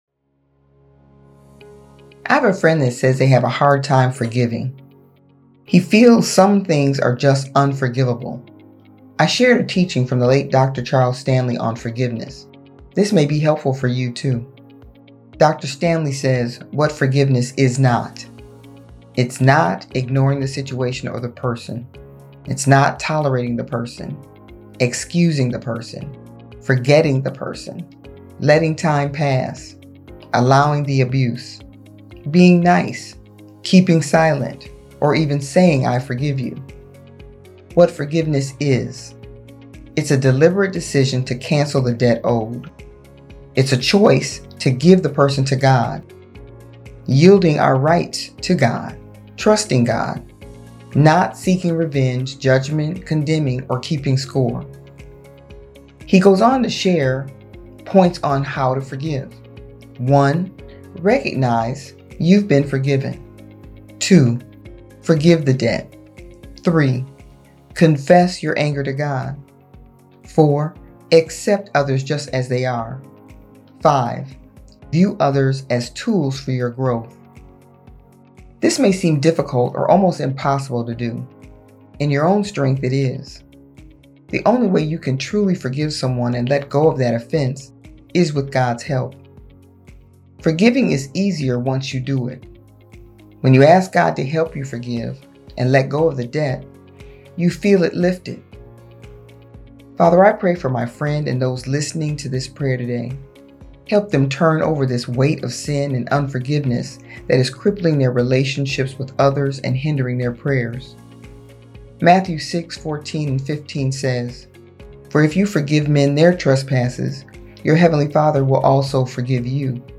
She shares words of inspiration, encouragement, and prayer.